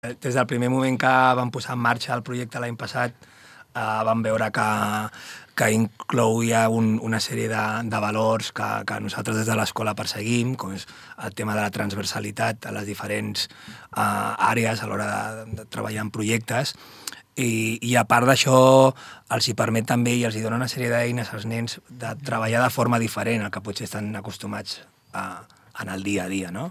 Píndola radiofònica: tornen les cooperatives escolars!